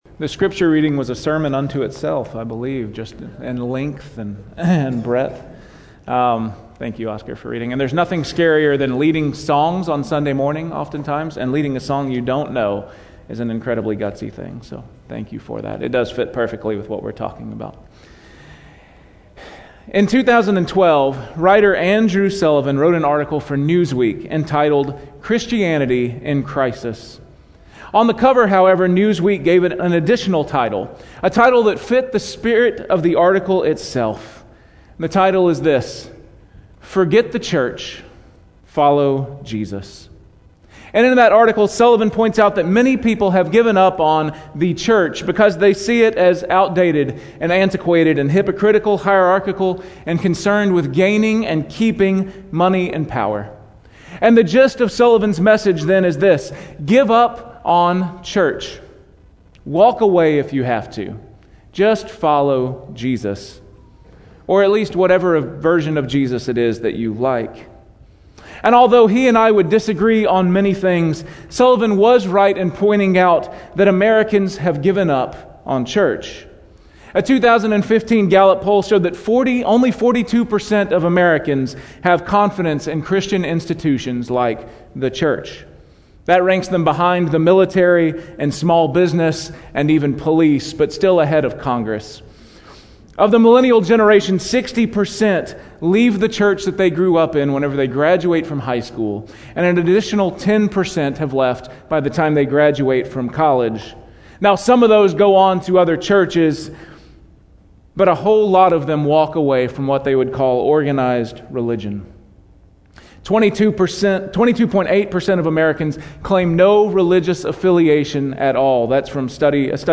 Weekly Sermon Audio “Church?